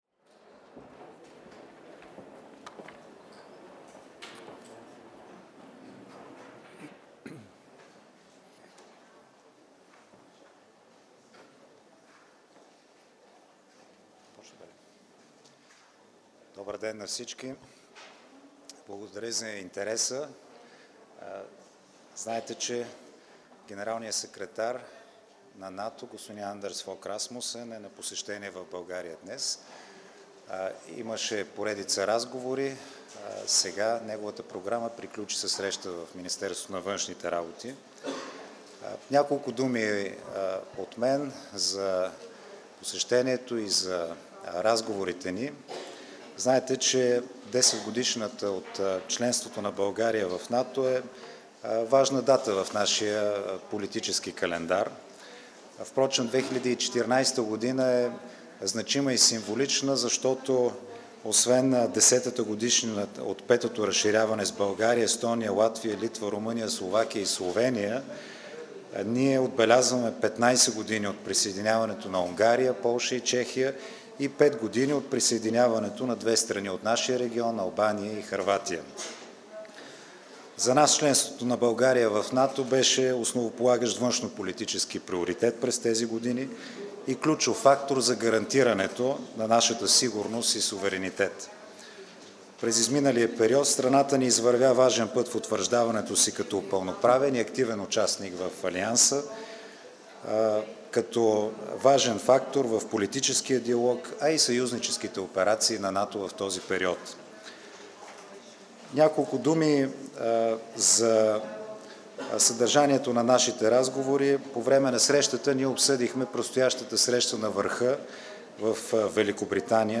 Joint press point with NATO Secretary General Anders Fogh Rasmussen and the President of Bulgaria, Rosen Plevneliev